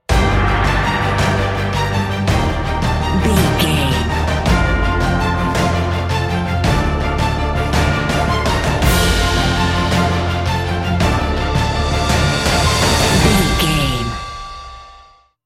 Epic / Action
Uplifting
Aeolian/Minor
heavy
intense
pompous
brass
cello
choir
drums
horns
strings
synthesizers
violin
hybrid